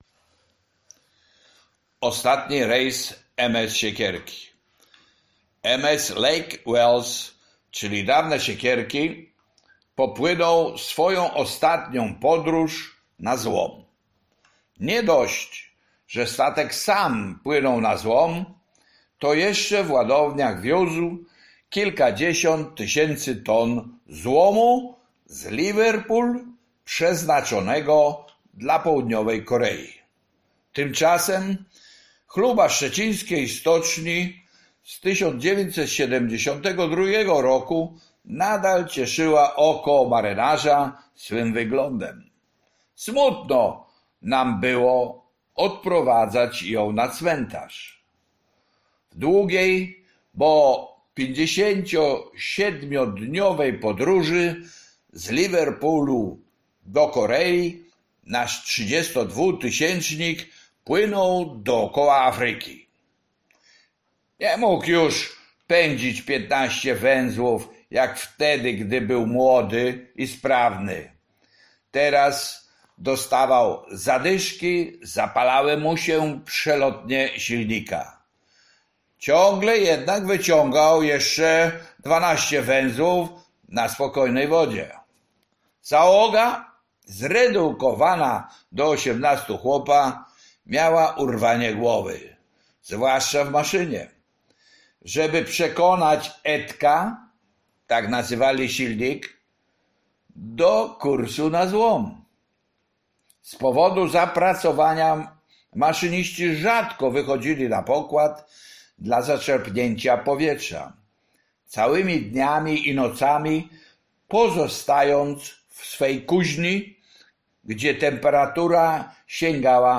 Życie marynarskie (audiobook). Rozdział 35 - Ostatni rejs MS Siekierki - Książnica Pomorska